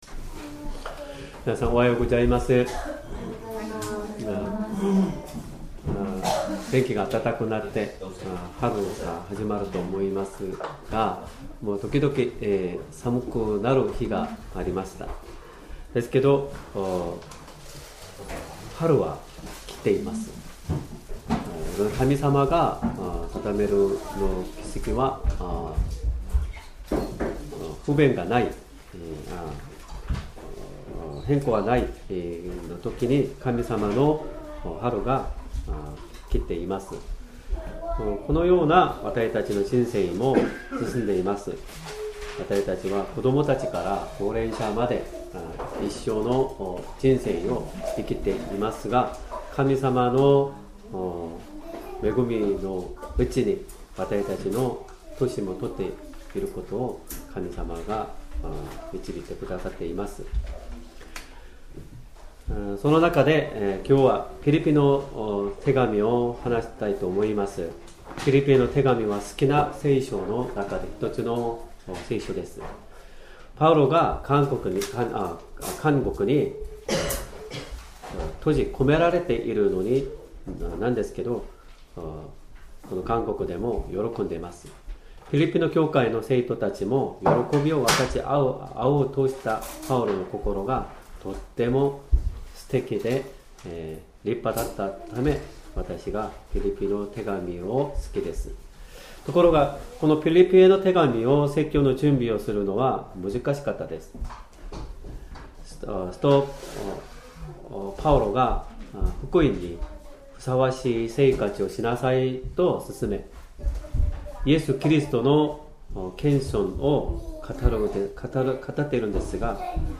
Sermon
2025年4月6日 主日礼拝 説教 「キリスト・イエスのうちにあるこの思いを抱きなさい」 聖書 ピリピ人への手紙 ２章１～１８節 2:1 ですから、キリストにあって励ましがあり、愛の慰めがあり、御霊の交わりがあり、愛情とあわれみがあるなら、 2:2 あなたがたは同じ思いとなり、同じ愛の心を持ち、心を合わせ、思いを一つにして、私の喜びを満たしてください。